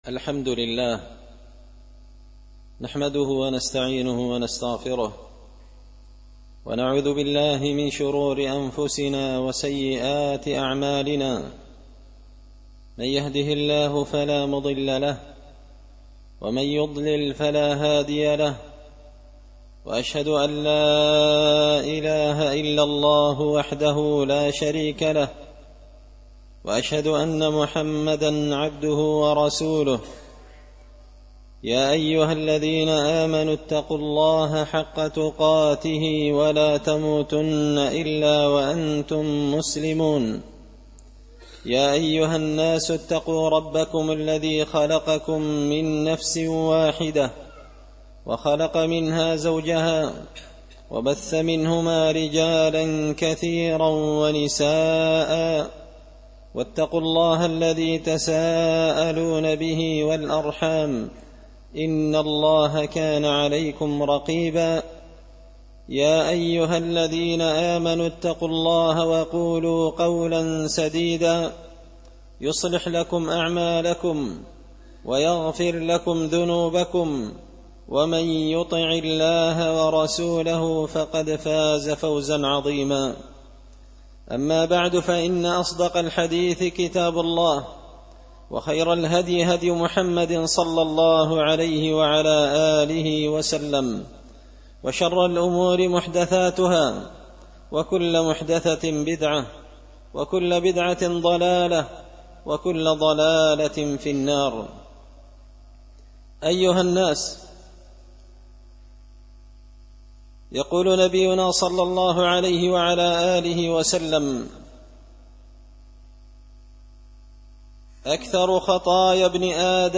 خطبة جمعة بعنوان – القالة بين الناس
دار الحديث بمسجد الفرقان ـ قشن ـ المهرة ـ اليمن